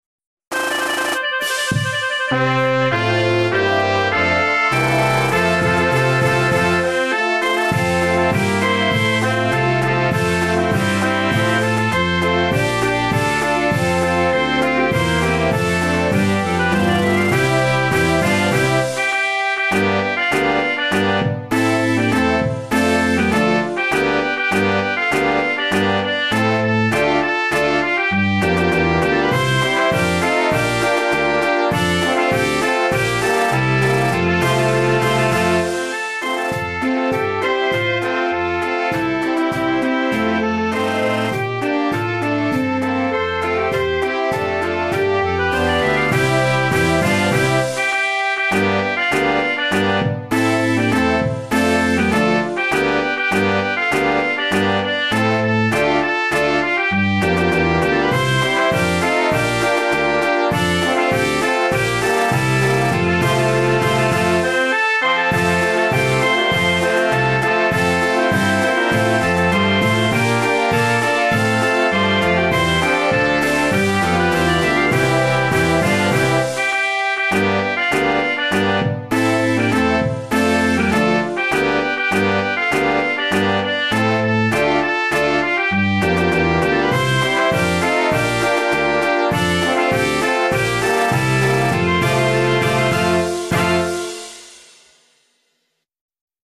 กราวกีฬา เวอร์ชั่นกรมพละ โน้ตเครื่อง Brass (ลงใหม่